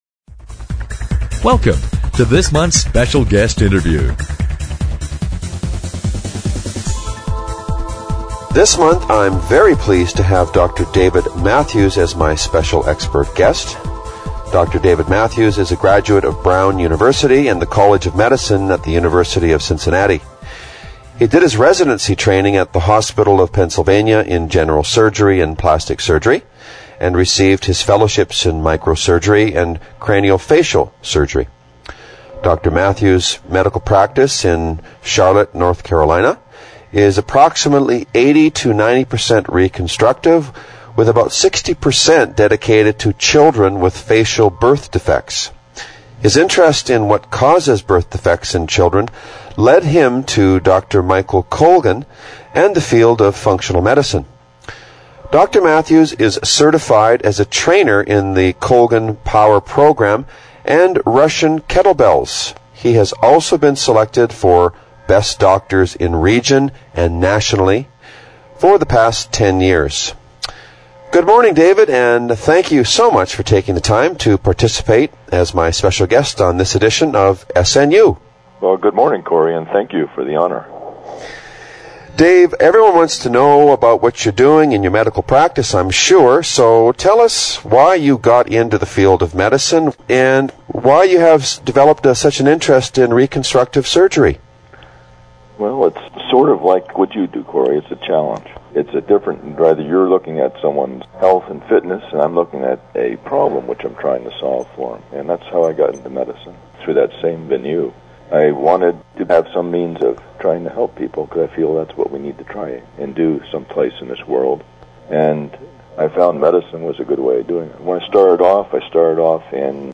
Special Guest Interview Volume 5 Number 1 V5N9c